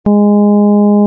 Κε = 440Hz
Κλίμακα Νη-Νη'
Οἱ ἤχοι ἔχουν παραχθεῖ μὲ ὑπολογιστὴ μὲ ὑπέρθεση ἀρμονικῶν.